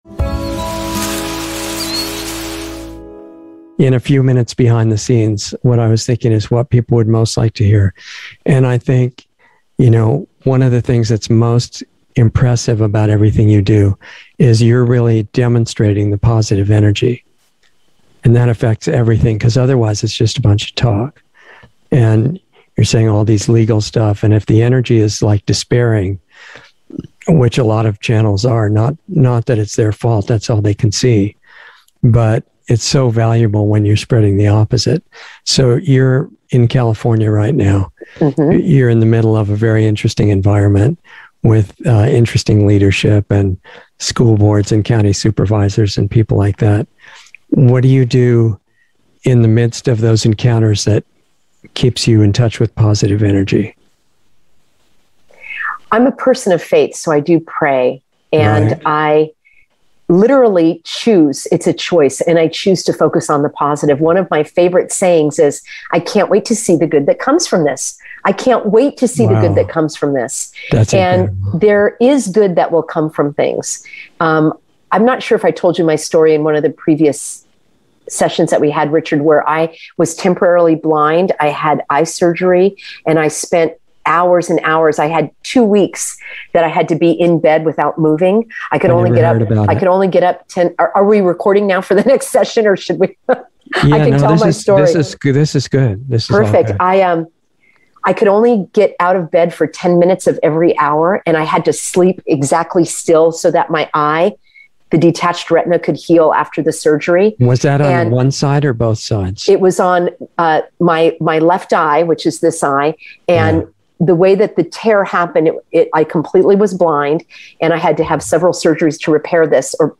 Insider Interview 9/8/21